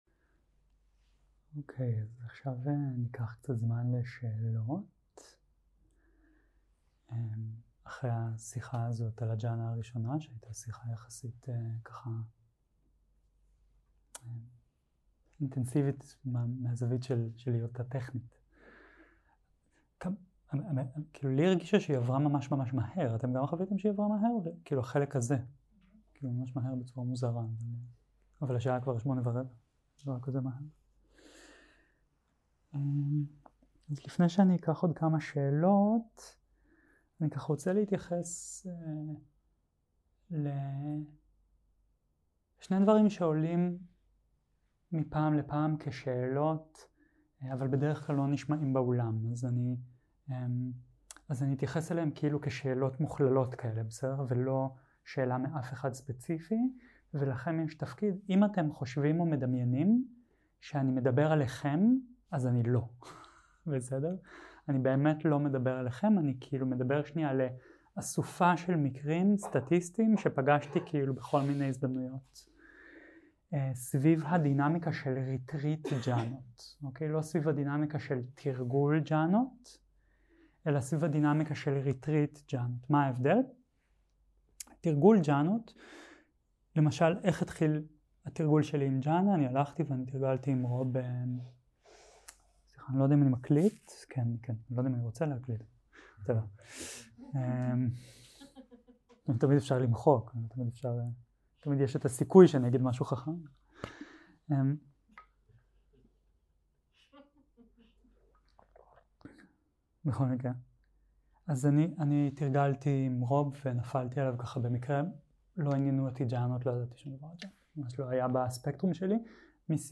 יום 7 - הקלטה 12 - ערב - שאלות ותשובות - לאחר שיחה על הג'האנה הראשונה 0:00 0:00 סוג ההקלטה: Dharma type: Questions and Answers שפת ההקלטה: Dharma talk language: Hebrew